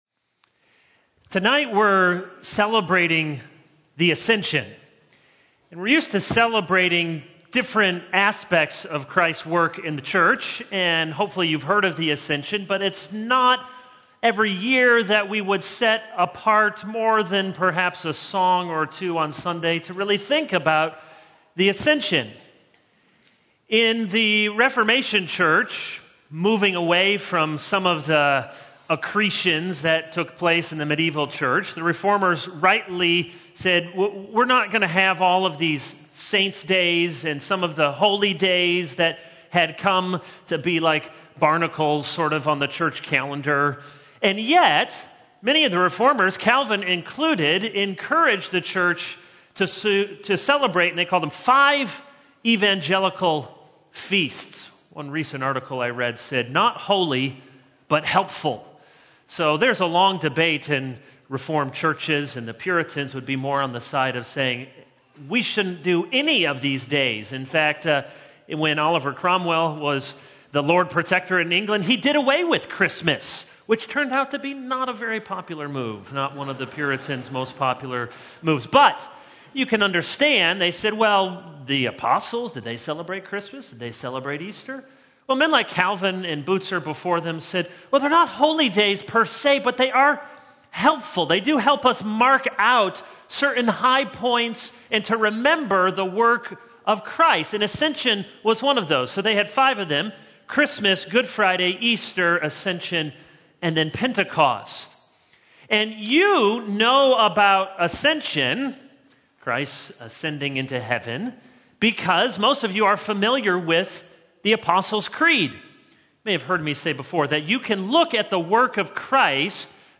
All Sermons Joel 0:00 / Download Copied!